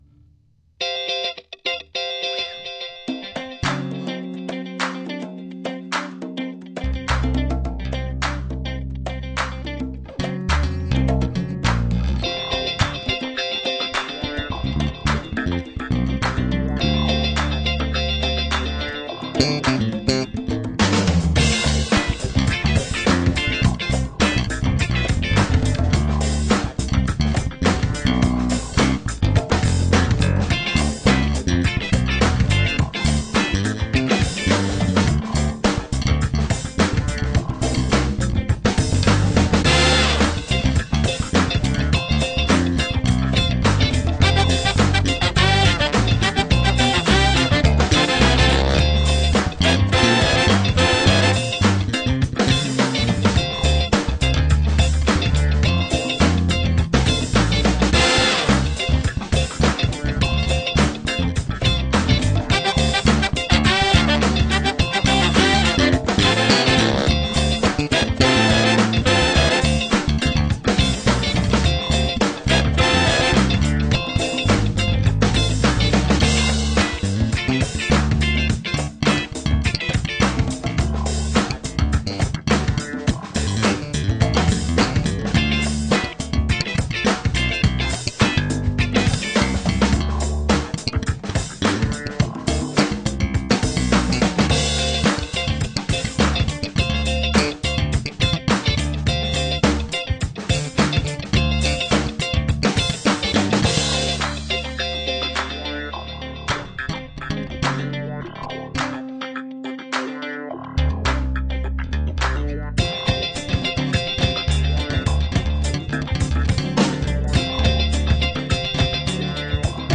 jam track in D
Nahravka paradni smile basa ocividne pekne zpracovana smile ale ta barva mi nesedi smile